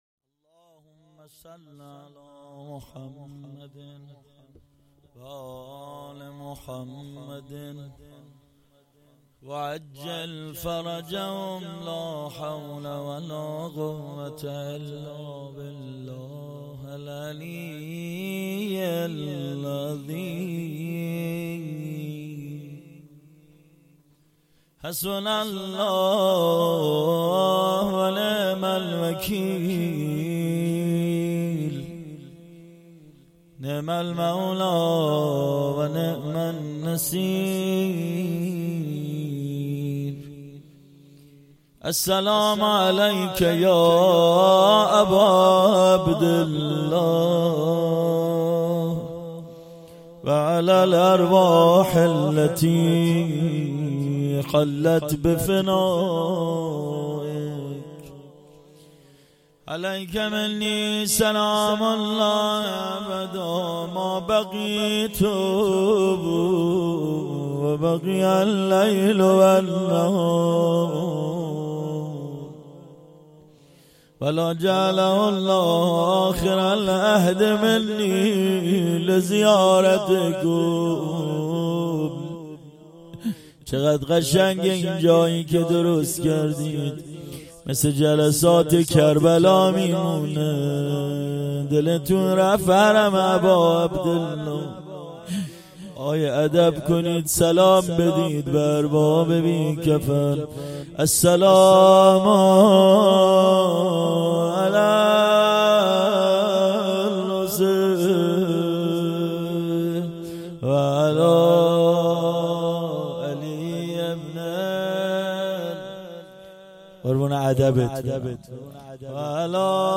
روضه
شب دوم مراسم دهه آخر صفر ۹۹